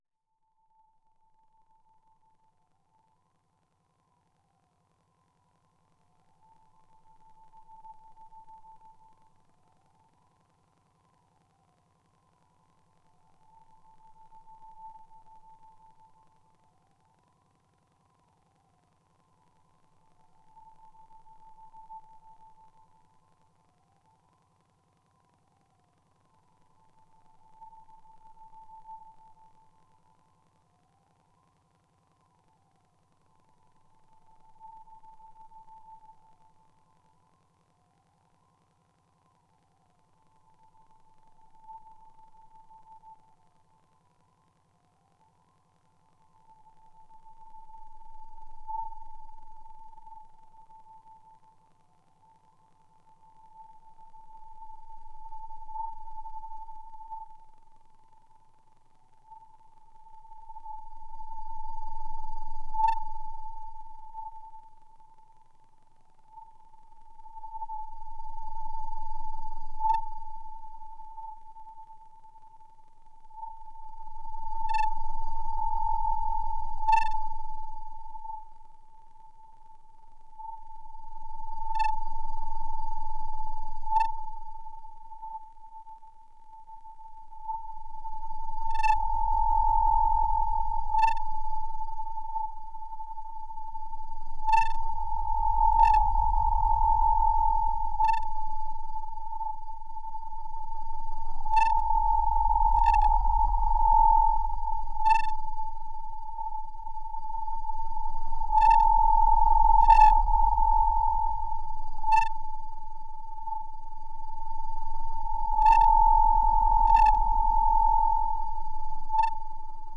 Exploration sur le modulaire + impro sur Model-D au ruban.
Comme des chants de grenouilles d'un autre monde, d'une autre planète, créatures improbables qui hantent un marais sombre et libre de toute culture d'aspect humain. Quelques cyber-insectes s'y faufilent, discrets et inoffensifs jusqu'à ce qu'un souffle de vent vienne tout calmer.
Pénètre-t-on un autre rêve, plus brutal, plus agité ? Peut-être, jusqu'à ce que tout s'éteigne.